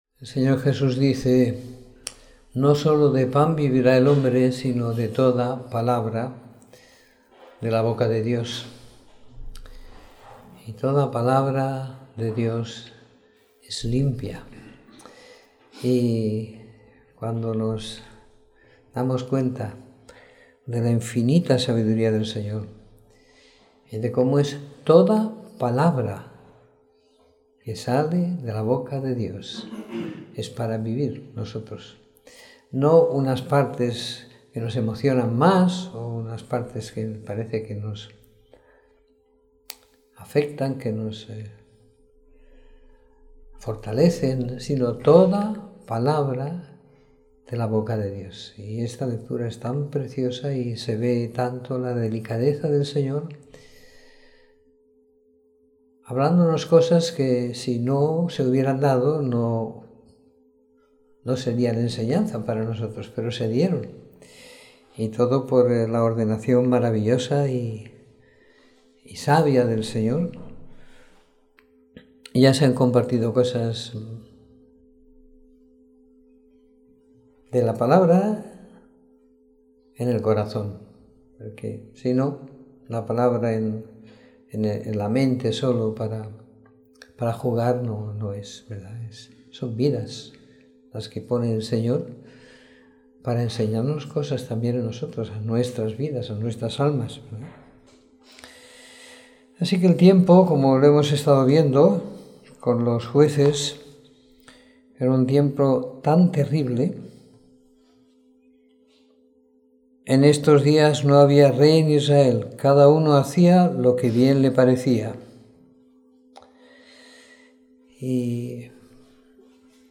Comentario en Rut 1 - 1º Samuel / Salmos 93 - 97 - 27 de Mayo de 2016
Reunión del Viernes